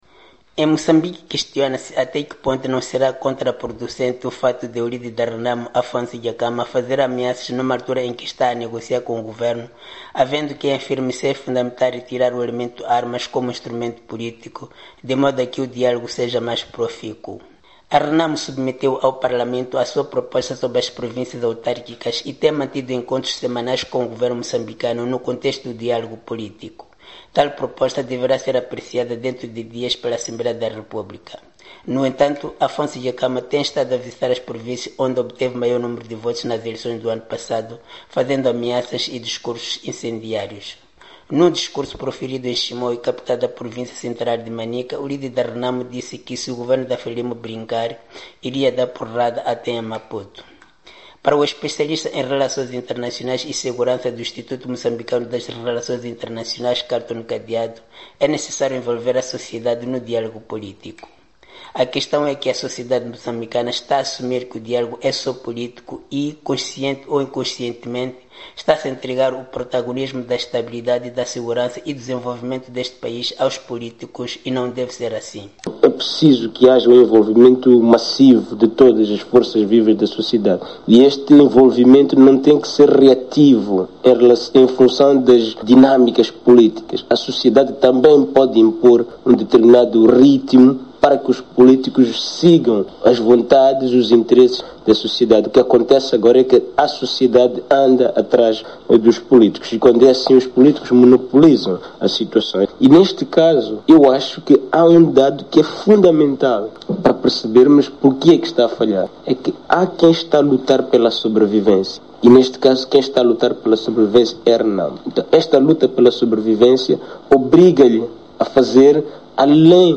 RENAMO e a chantagem politica - reportagem